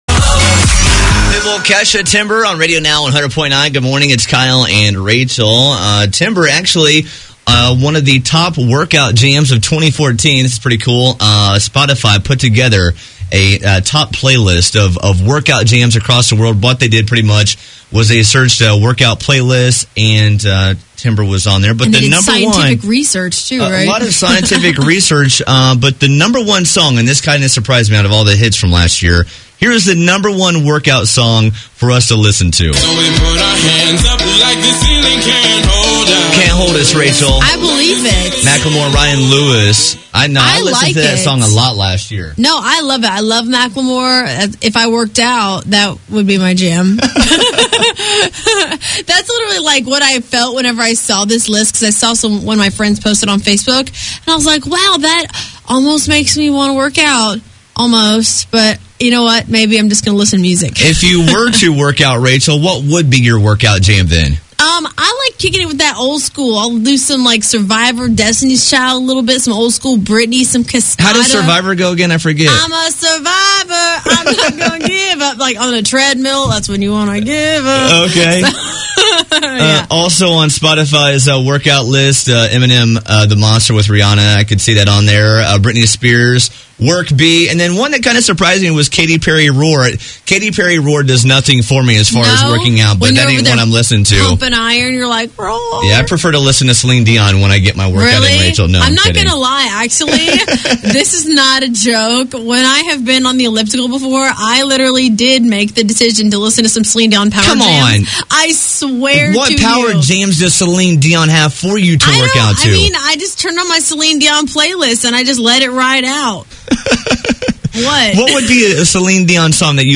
talk workout anthems: